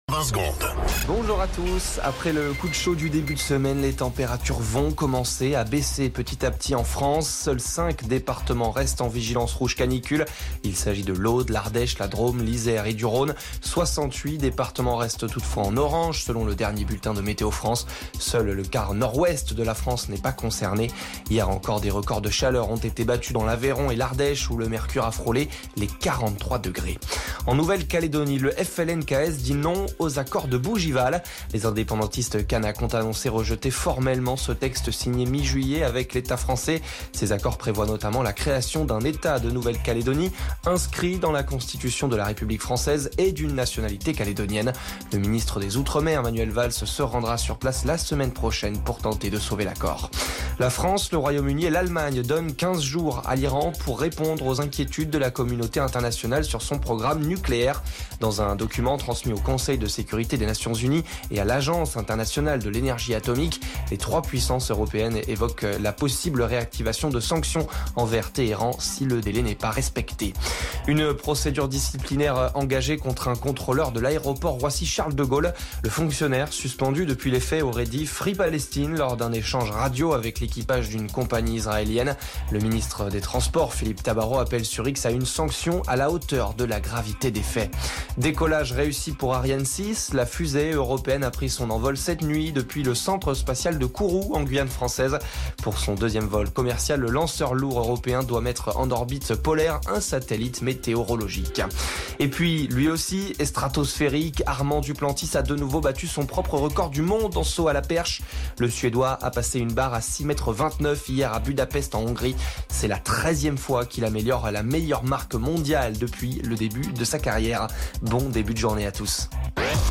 Flash Info National 13 Août 2025 Du 13/08/2025 à 07h10 .